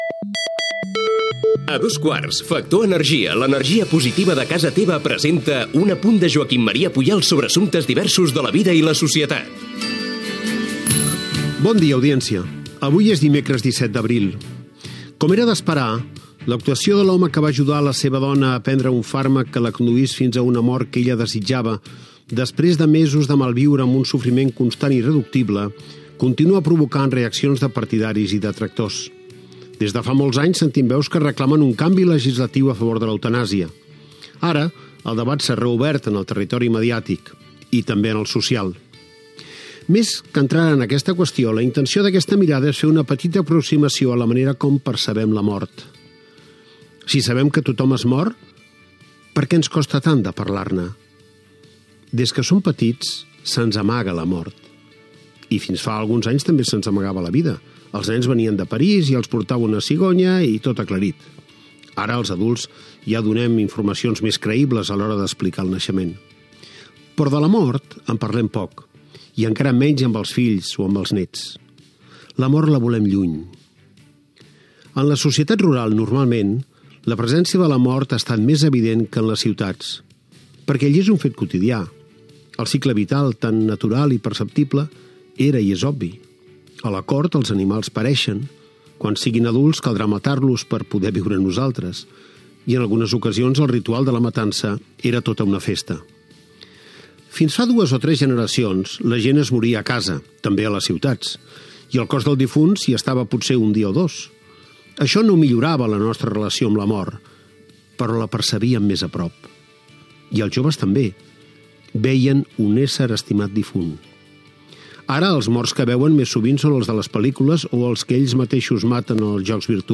Careta de le secció amb publicitat. Reflexió sobre la mort i de com la percebem.
Info-entreteniment